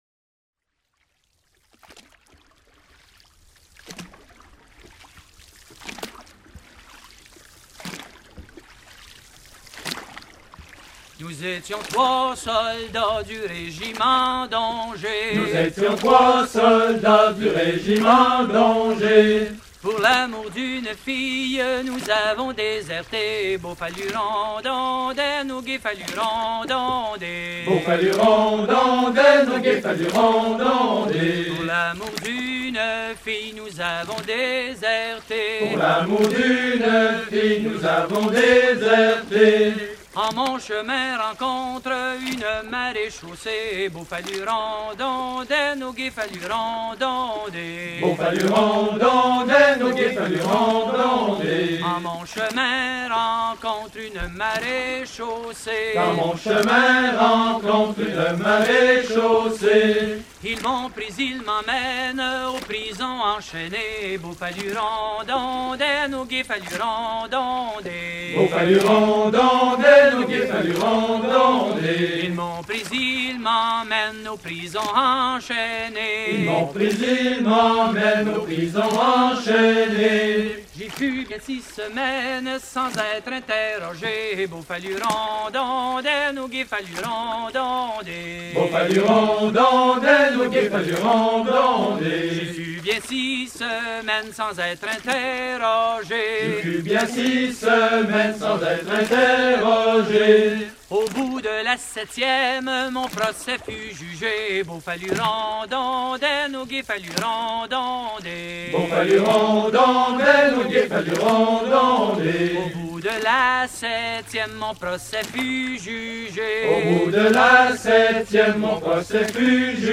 Fonction d'après l'analyste gestuel : à ramer ;
Genre laisse
Catégorie Pièce musicale éditée